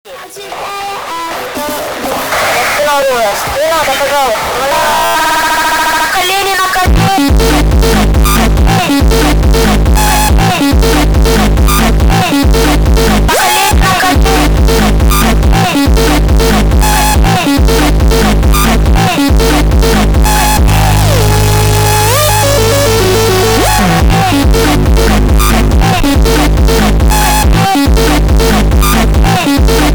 Жанр: R&b / Соул / Фанк